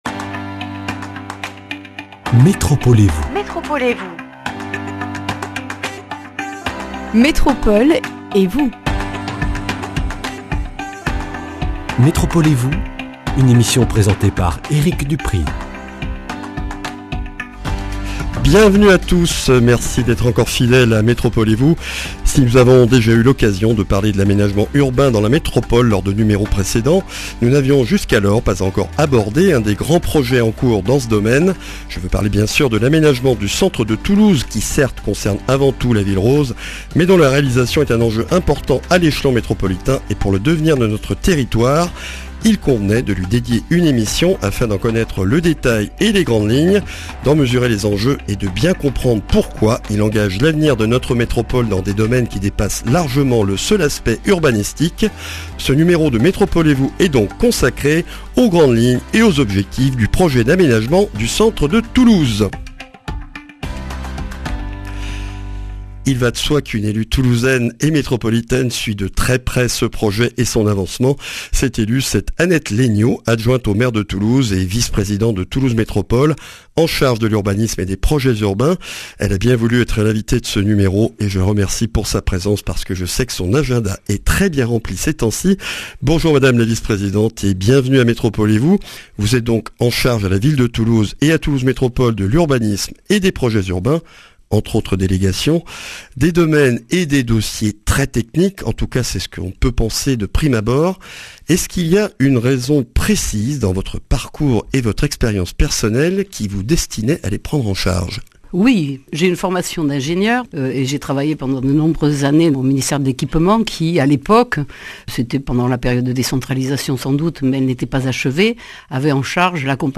Une émission avec Annette Laigneau, adjointe au maire de Toulouse et Vice-présidente de Toulouse Métropole chargée de l'Urbanisme et des Projets (...)
Speech